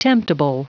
Prononciation du mot temptable en anglais (fichier audio)
Prononciation du mot : temptable